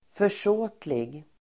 Ladda ner uttalet
Uttal: [för_s'å:tlig]